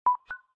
Charger_Connection.ogg